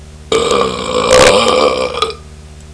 An inappropriate sound
belch2.wav